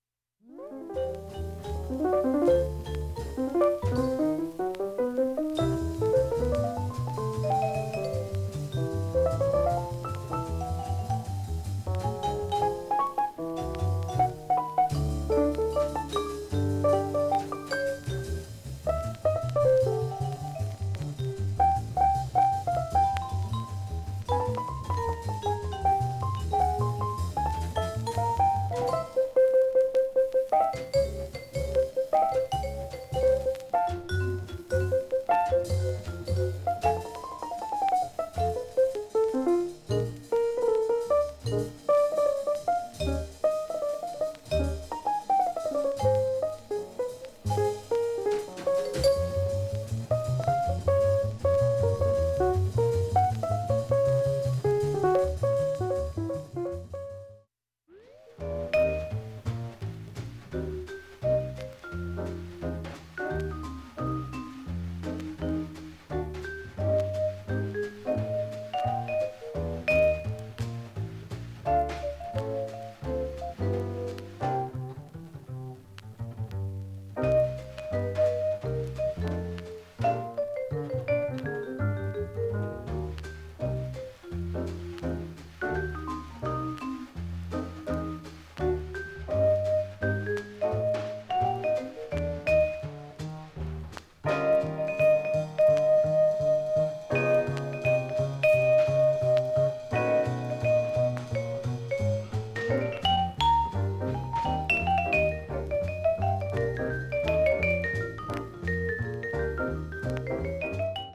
バックチリの無くいい音質です。 たまに３回以内プツ出る程度（１５箇所） 盤面もきれいです。
５回までのかすかなプツが１箇所
Repress, Mono